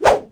HandSwing5.wav